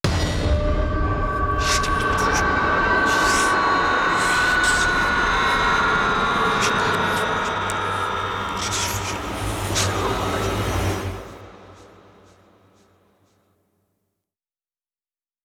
06_公寓楼道_鬼差偷看.wav